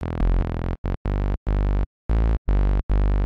Sample sounds, mostly quite short